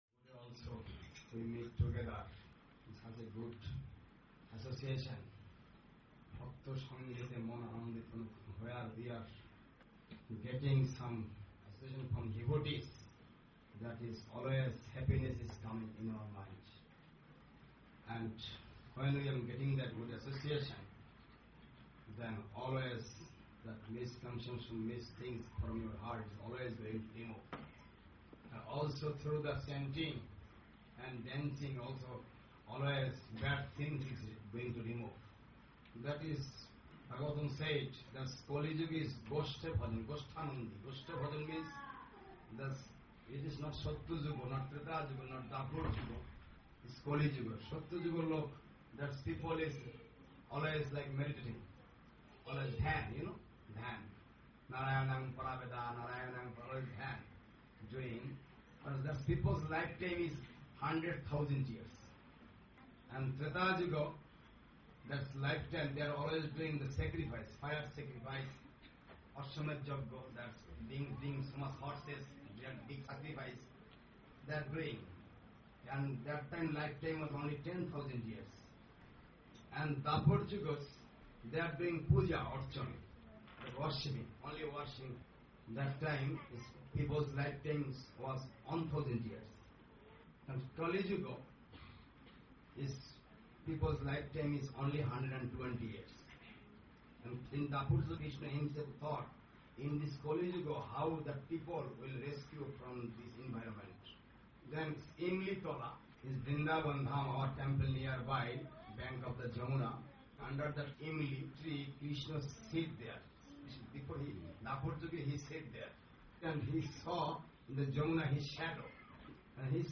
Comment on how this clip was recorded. Caracas, Venezuela